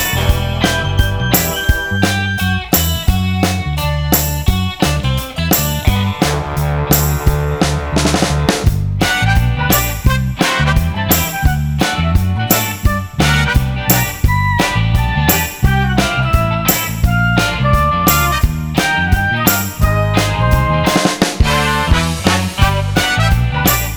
No Backing Vocals Soul / Motown 2:50 Buy £1.50